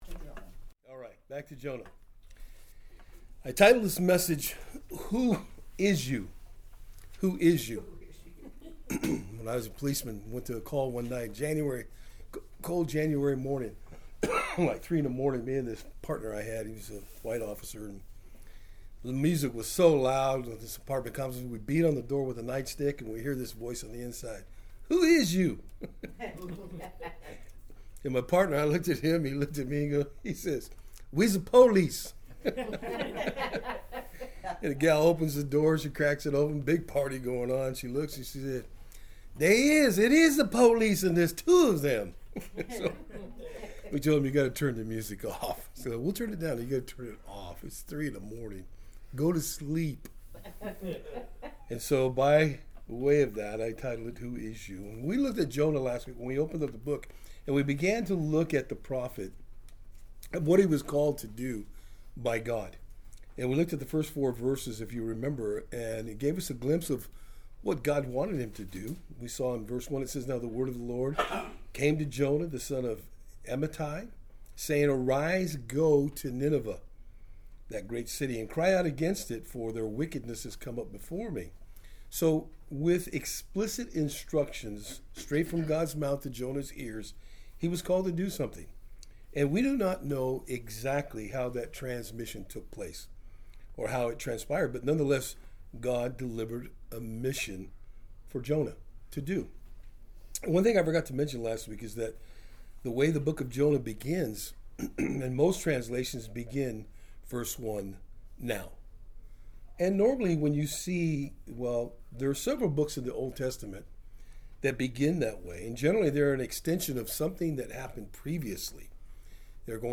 Jonah 1:4-7 Service Type: Thursday Afternoon Jonah doesn’t want to do what the Lord has called him to do.